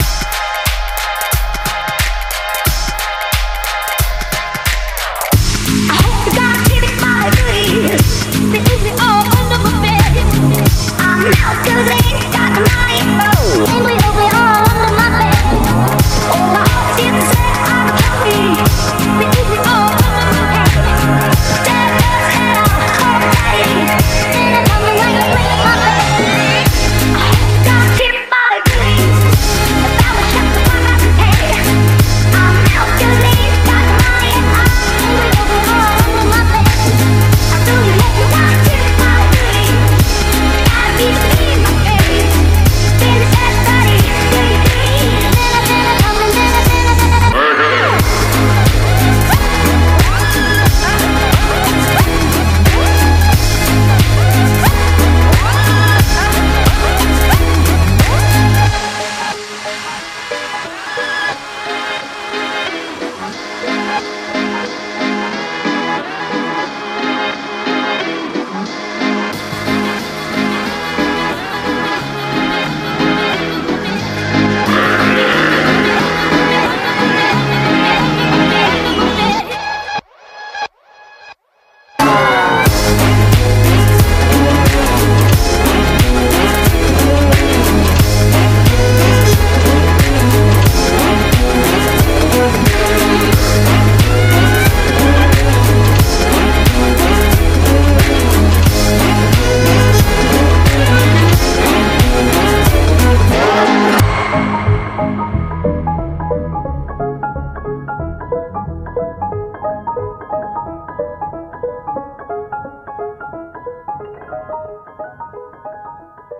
BPM90-180